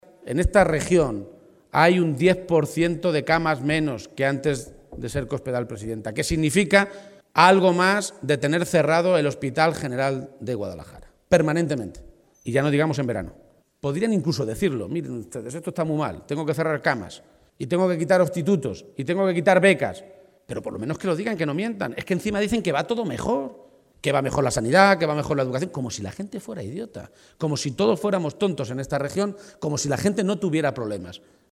“No podemos tolerar que eso quede políticamente impune”, ha afirmado durante un acto de campaña en la localidad de Marchamalo (Guadalajara), en la que ha sido necesario cambiar de ubicación ante la masiva afluencia de militantes y simpatizantes socialistas.